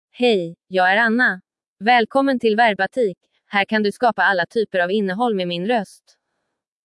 FemaleSwedish (Sweden)
AnnaFemale Swedish AI voice
Anna is a female AI voice for Swedish (Sweden).
Voice sample
Listen to Anna's female Swedish voice.
Anna delivers clear pronunciation with authentic Sweden Swedish intonation, making your content sound professionally produced.